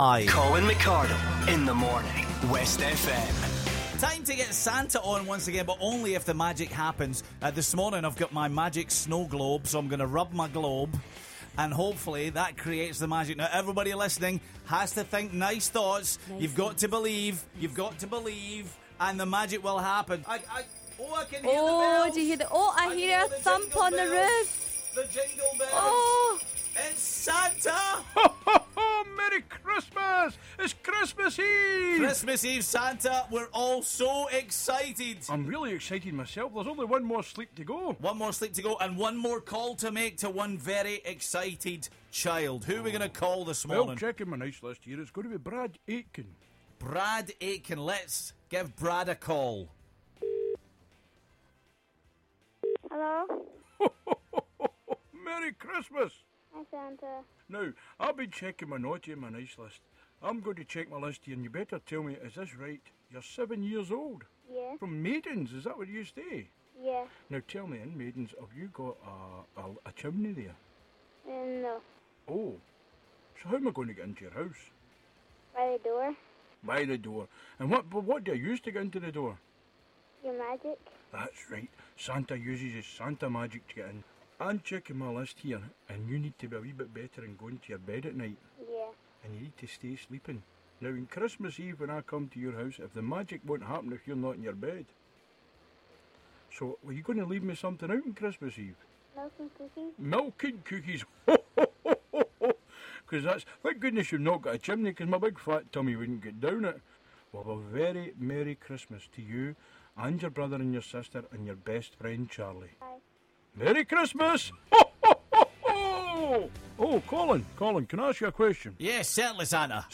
Santa popped into the West FM studios for the last time this week before he sets off on his annual duty!